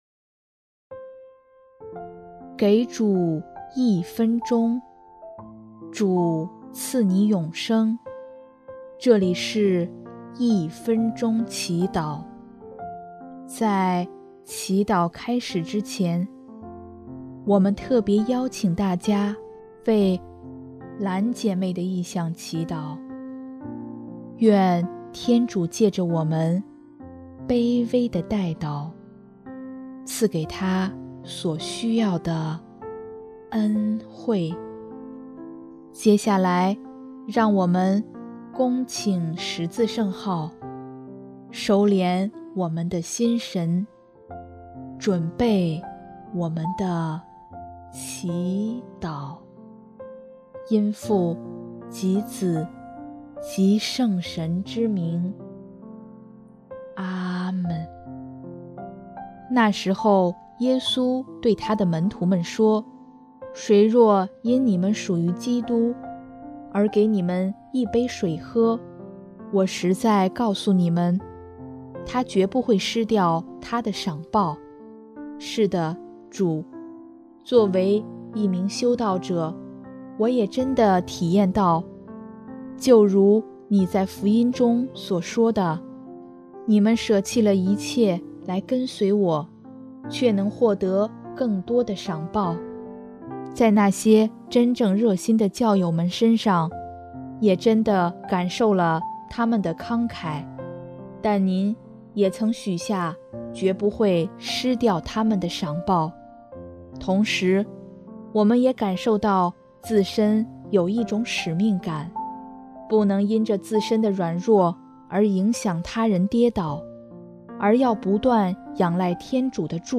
【一分钟祈祷】|2月27日 要成为他人的光